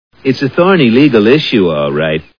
The Simpsons [Celebrities] Cartoon TV Show Sound Bites